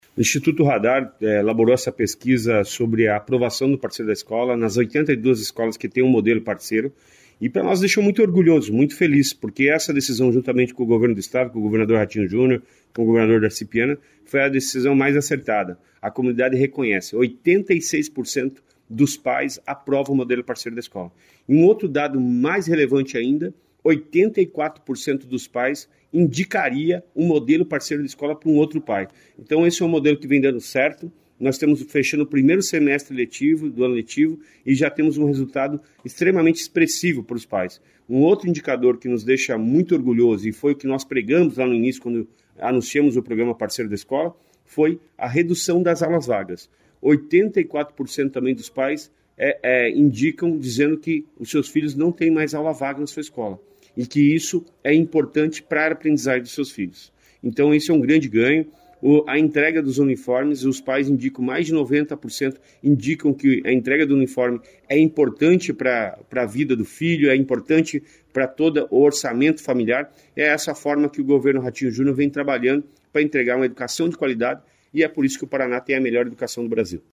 Sonora do secretário Estadual da Educação, Roni Miranda, sobre a aprovação do programa Parceiro da Escola após um semestre da implantação